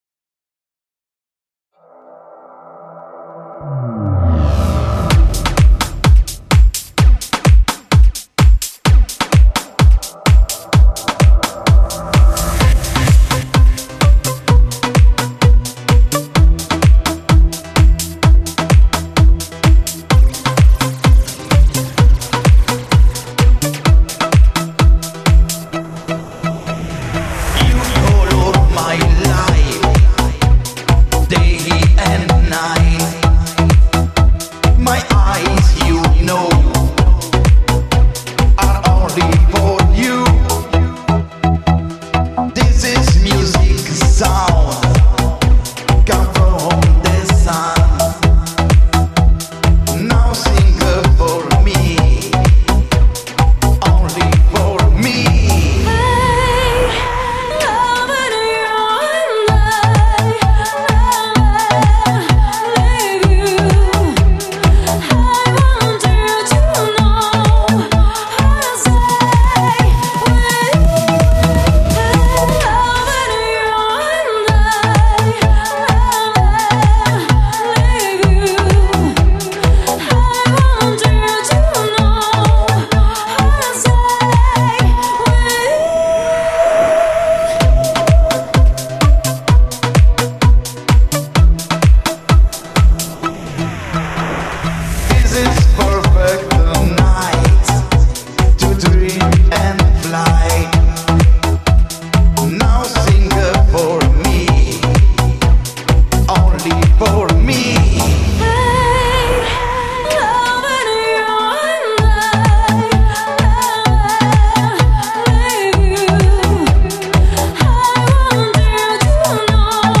sonorità house music con i caratteristici suoni analogici.